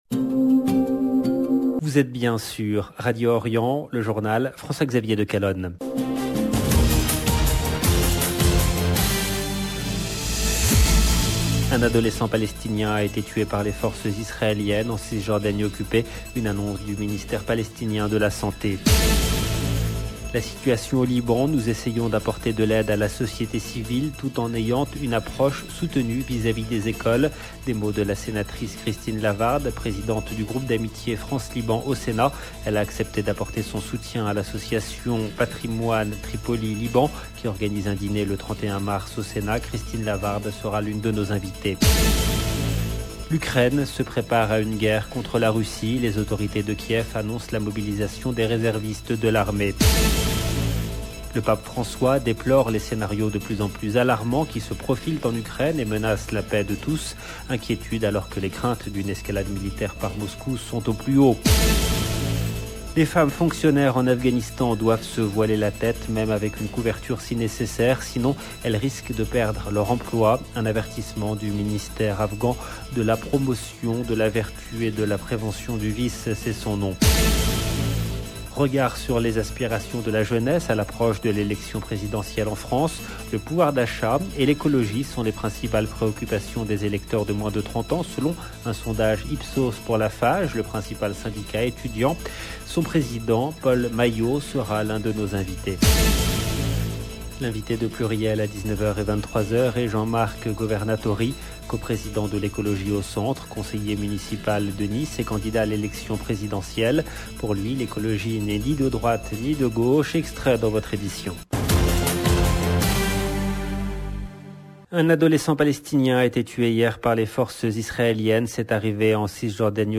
LE JOURNAL DU SOIR EN LANGUE FRANCAISE DU 23/02/22